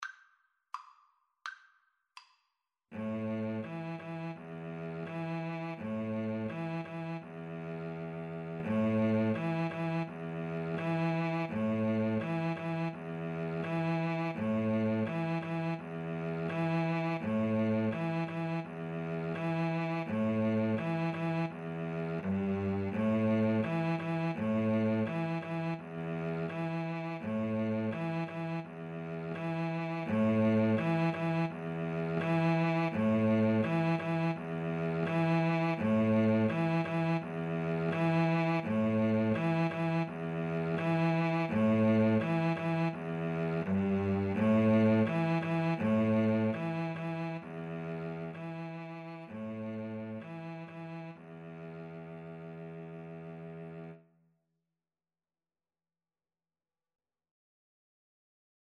Free Sheet music for Violin-Cello Duet
Steady two in a bar ( = c. 84)
A minor (Sounding Pitch) (View more A minor Music for Violin-Cello Duet )
2/2 (View more 2/2 Music)